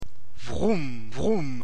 Vroum
Brumm
vroum.mp3